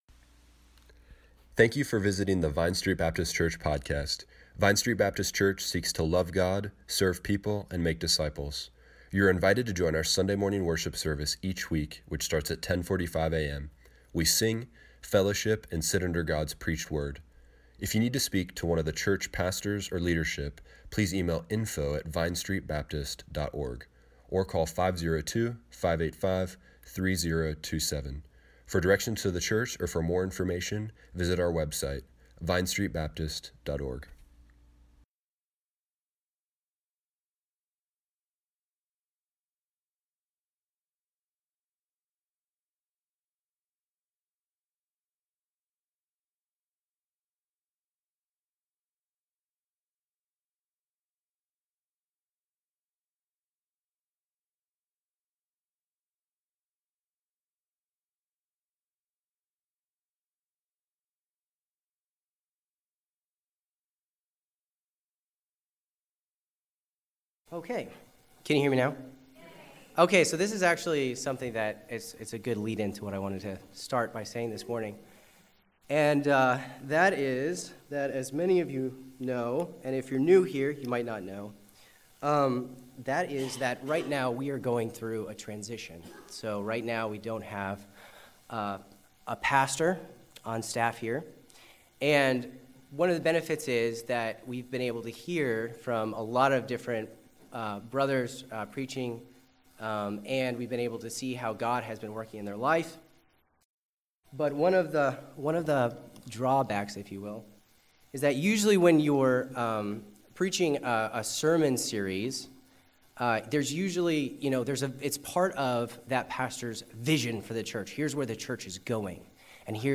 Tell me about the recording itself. Series Philippians: A Study in Unity Service Morning Worship Tweet Summary March 3, 2019 Click here to listen to the sermon online.